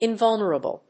音節in・vul・ner・a・ble 発音記号・読み方
/ìnvˈʌln(ə)rəbl(米国英語), ˌɪˈnvʌlnɜ:ʌbʌl(英国英語)/